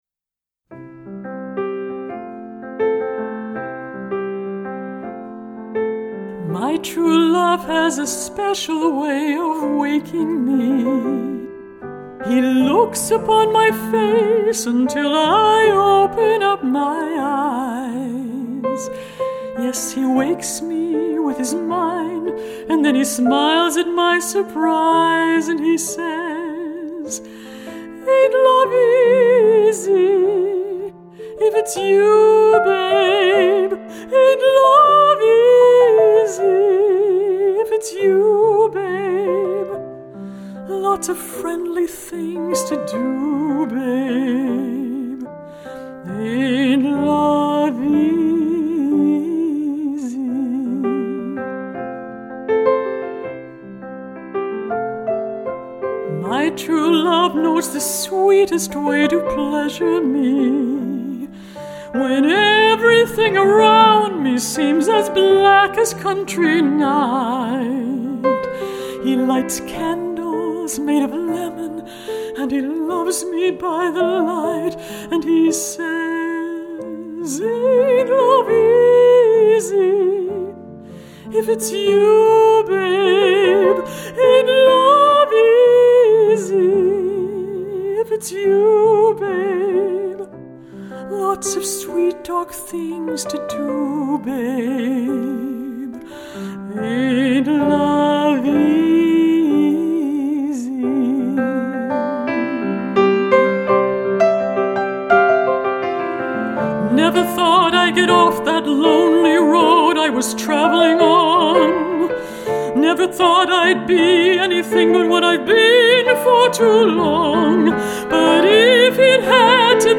now to the poignant & powerful song
piano